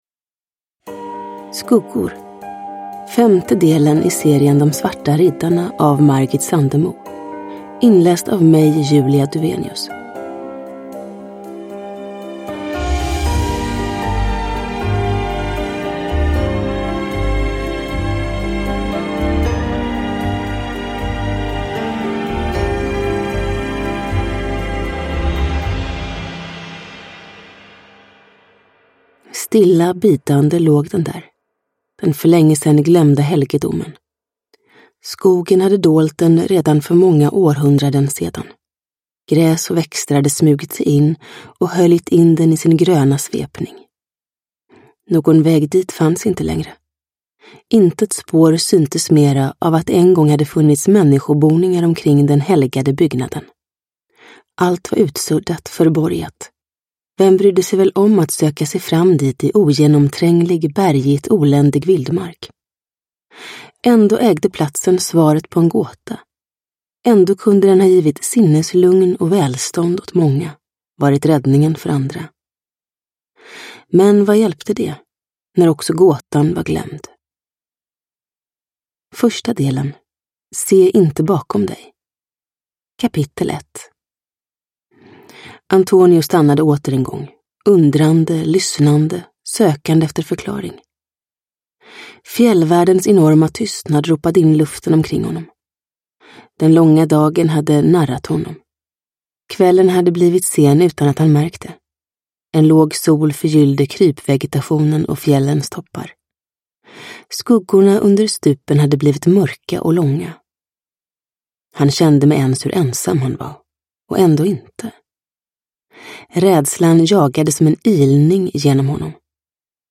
Skuggor – Ljudbok – Laddas ner